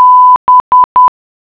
Nota.- Para escuchar el sonido, hacer clic en la clave morse correspondiente (el sonido se escuchará a una velocidad de 10 palabras/minuto).